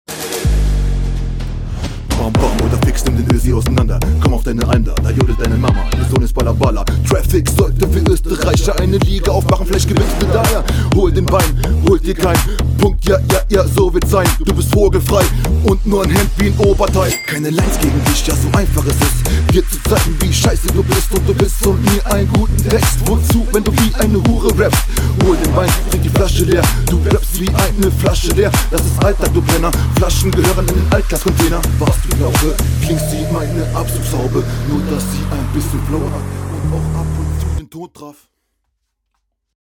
Okay, Party Beat ist am start.